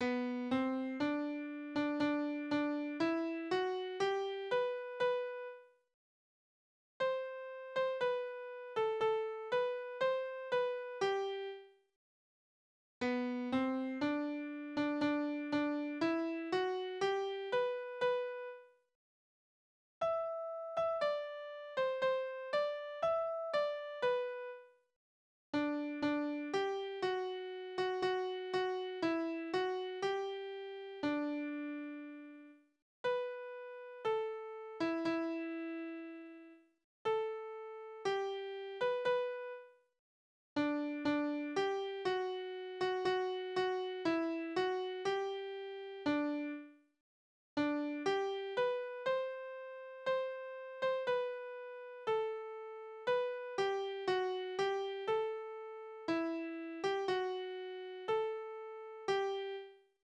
Tonart: G-Dur
Taktart: 2/4
Tonumfang: Oktave, Quarte
Besetzung: vokal